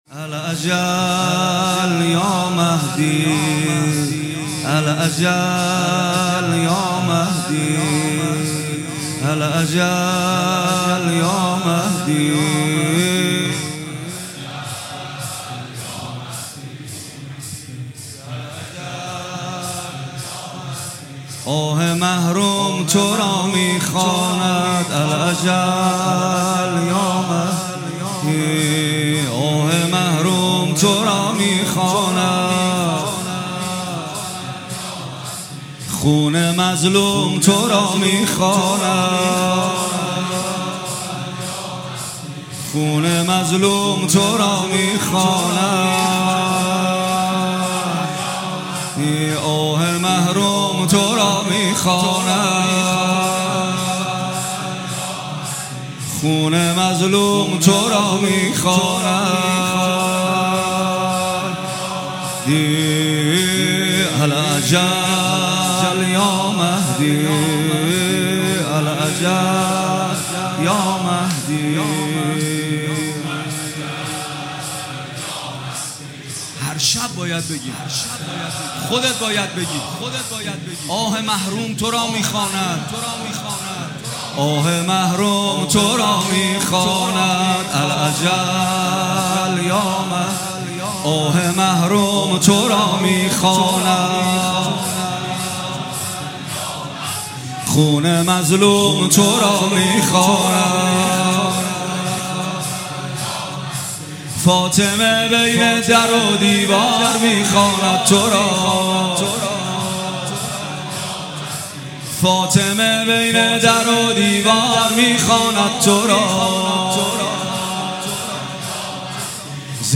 مداحی شب دوم محرم 1402 پویانفر